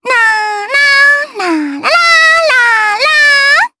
May-Vox_Hum_jp.wav